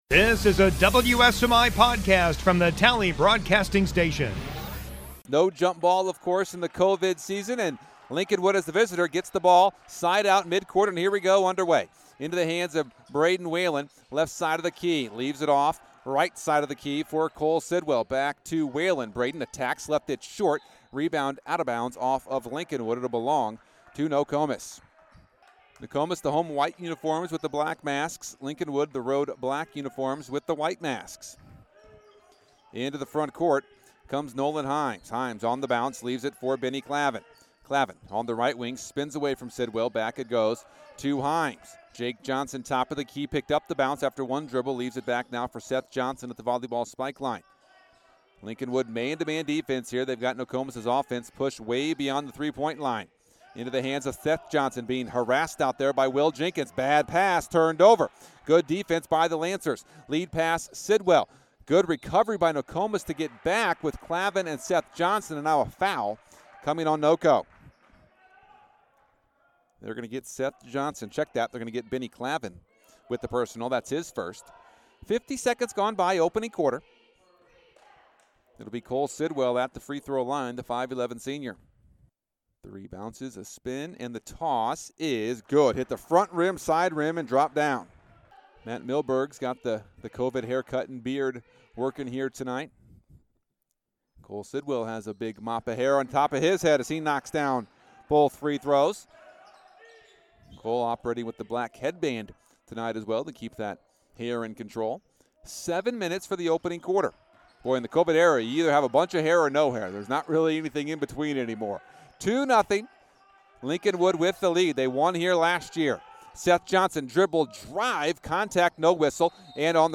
Podcasts - HS Sports Coverage
02/12/2021 Boys Basketball Lincolnwood at Nokomis